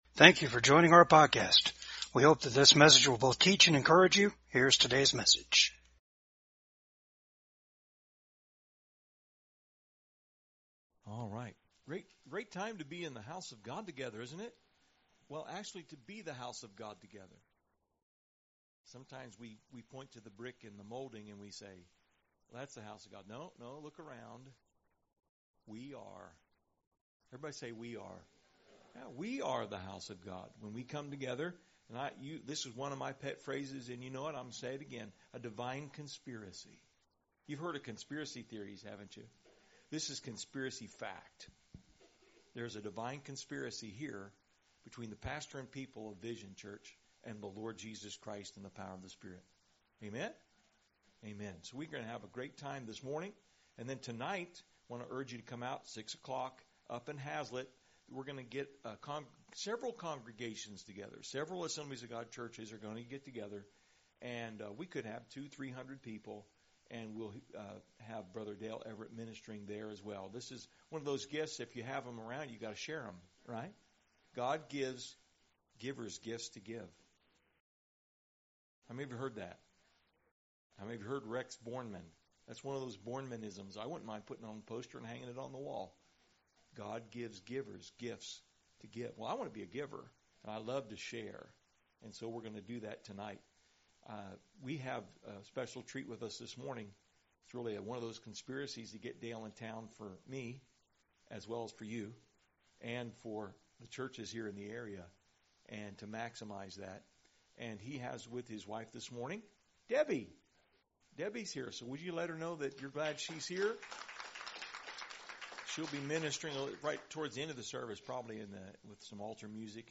Service Type: REFRESH SERVICE